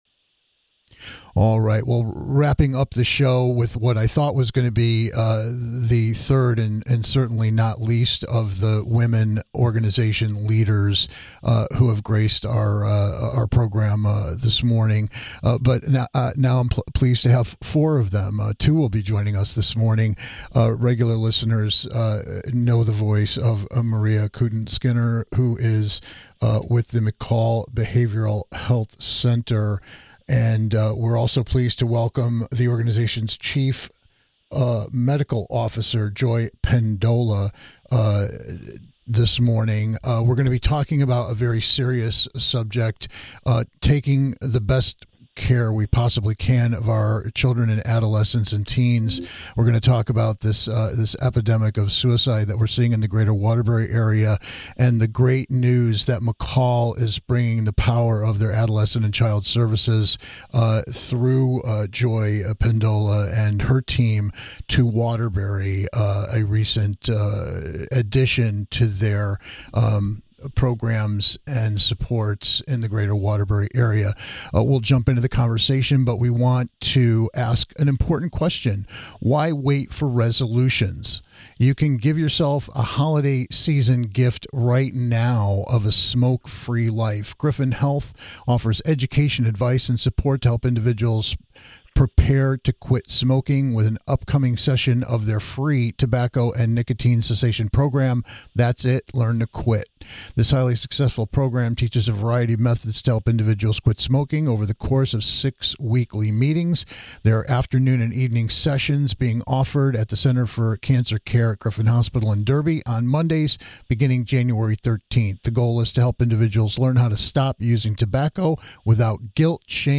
This holiday week we are diving into new interviews with several women leaders in their own areas of expertise who are helping you try to set a better course for the New Year.